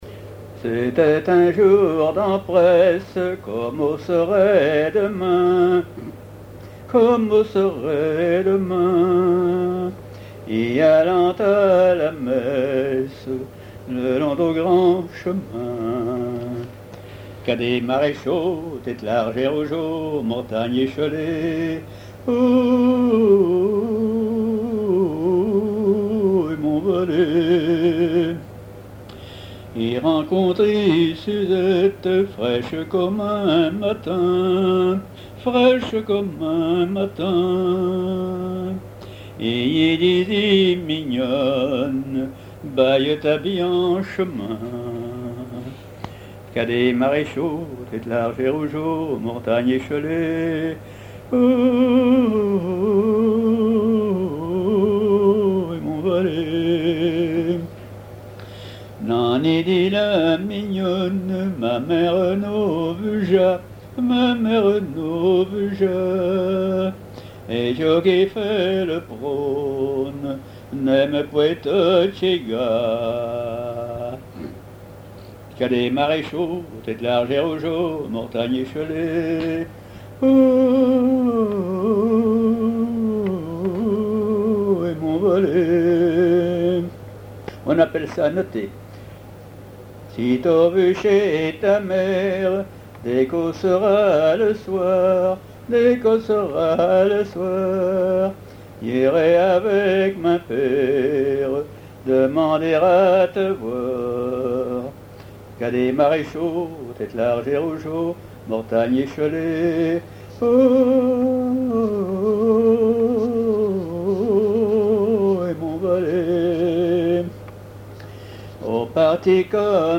Genre laisse
chansons à ripouner ou à répondre
Pièce musicale inédite